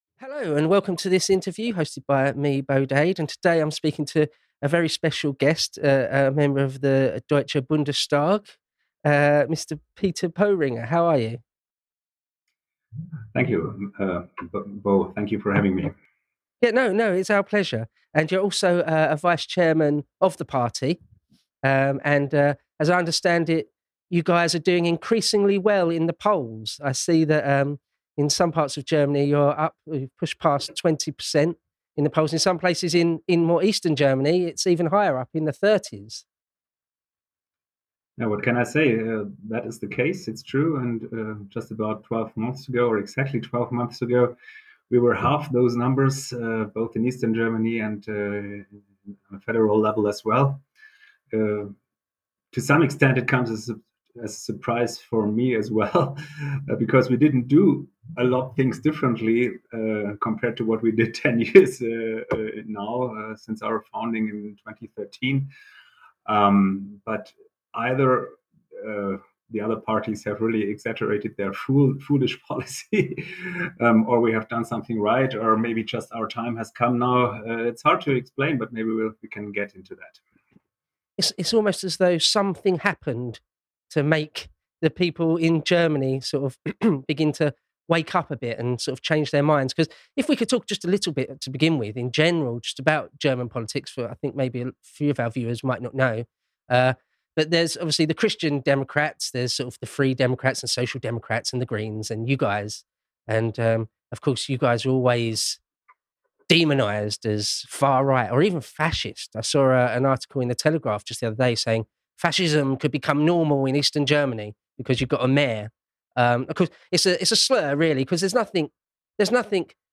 Alternative for Germany | Interview with Peter Boehringer